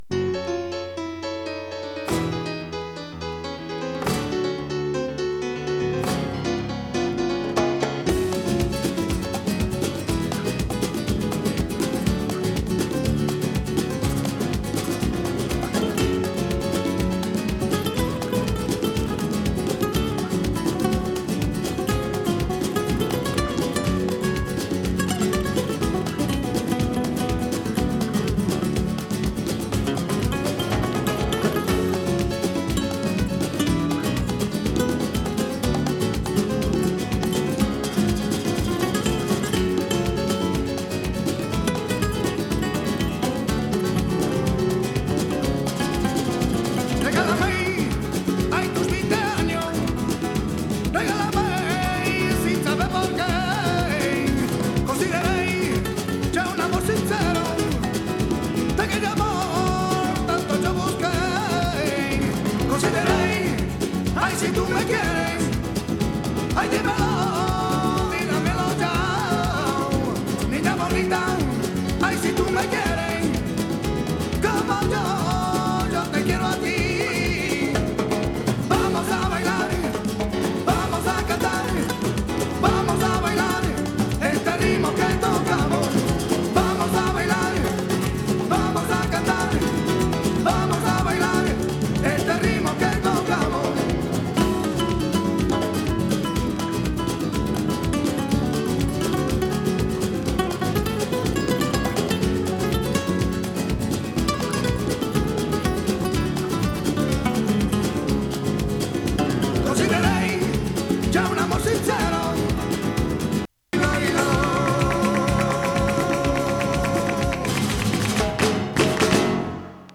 南仏出身のスペイン系メンバーによるグループ。
[3track 12inch]＊音の薄い部分に所々チリパチ・ノイズ。